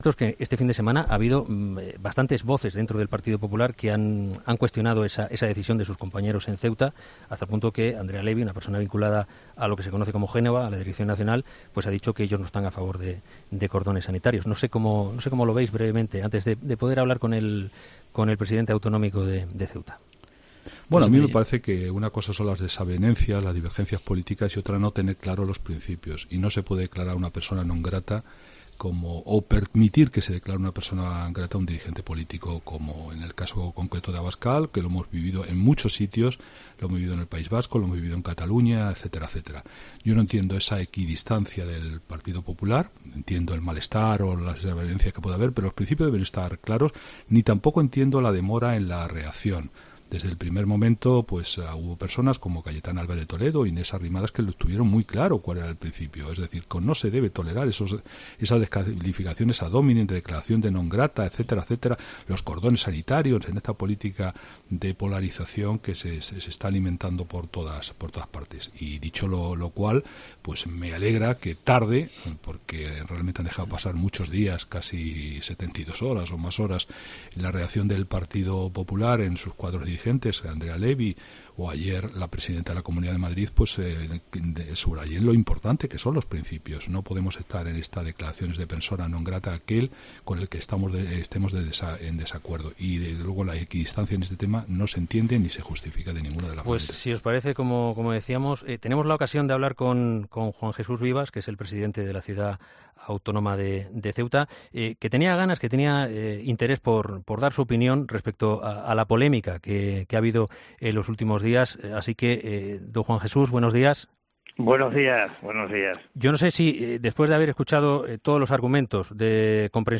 Juan Jesús Vivas, presidente de la ciudad autónoma ha respondido en COPE a los motivos por los que el PP se abstuvo en la declaración de Santiago...
Juan Jesús Vivas, presidente de la ciudad autónoma de Ceuta ha pasado por los micrófonos de 'Herrera en COPE' tras una polémica votación en la Asamblea de la ciudad autónoma en la que se ha declarado a Santiago Abascal, con la abstención del PP, 'persona non grata'.